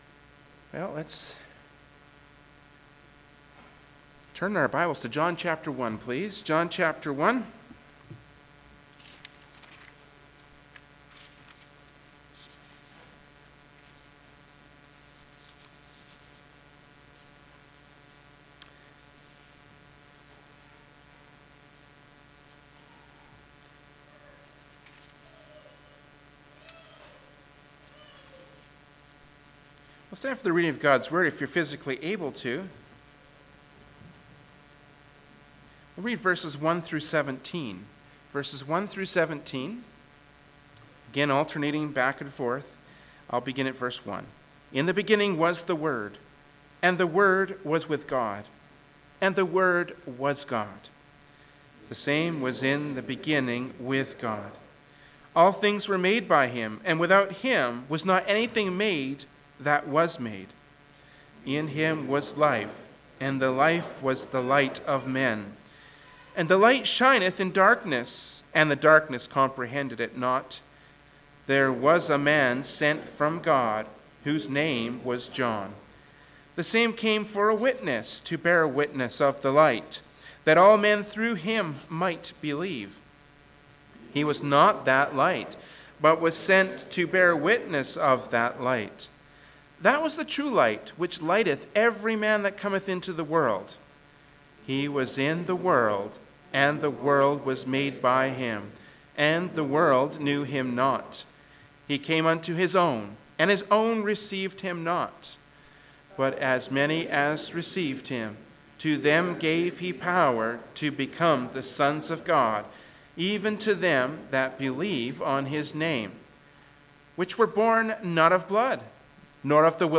Genre: Preaching.
Passage: John 1:1-17 Service Type: Sunday Morning Worship Service